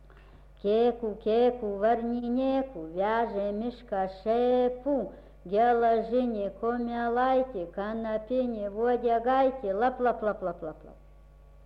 vokalinis
Tik vienas garso kanalas visam KF.